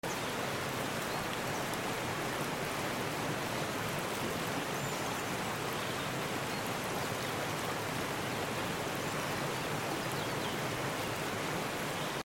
Pushing through a lush landscape sound effects free download
The sound of rushing water blends with birdsong and the rustle of branches, creating a peaceful escape deep in the Pacific Northwest wilderness 😌 Pushing Through A Lush Landscape Sound Effects Free Download.